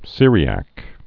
(sîrē-ăk)